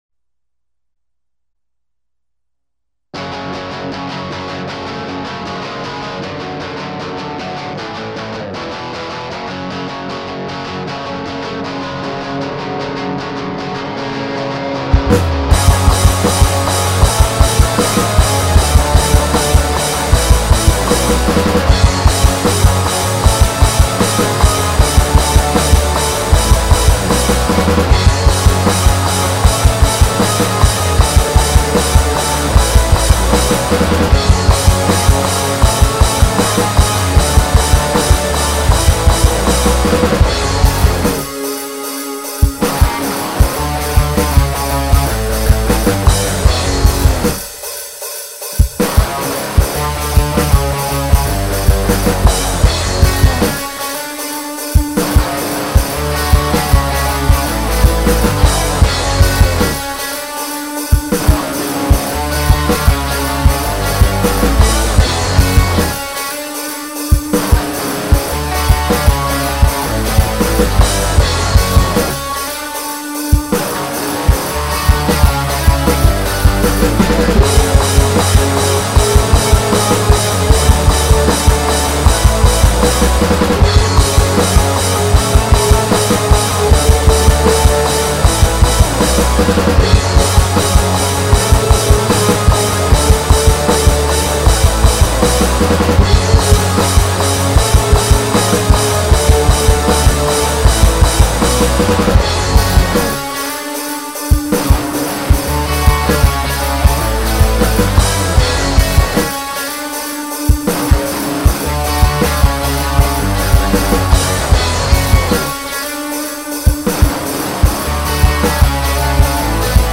Drummer for Heavy/Hard Rock band